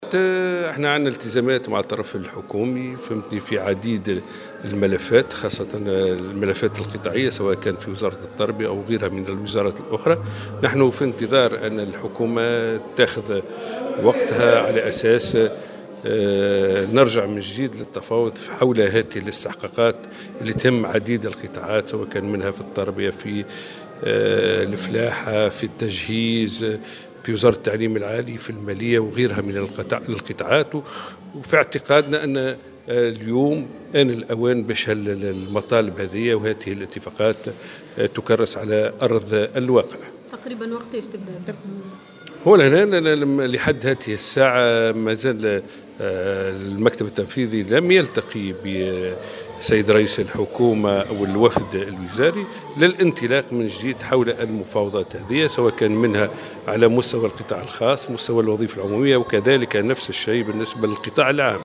وتابع في تصريح على هامش تظاهرة حول اليوم العالمي للمرأة في سوسة، أن الاتحاد في انتظار استعداد الحكومة للرجوع إلى المفاوضات من جديد حول الأجور. وأضاف أن المكتب التنفيذي للاتحاد لم يلتق بعد مع الحكومة لاطلاق المفاوضات التي ستتعلق بالقطاع الخاص والوظيفة العمومية والقطاع العام .